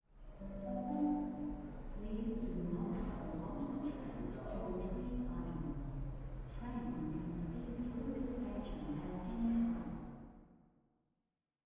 announcement2.ogg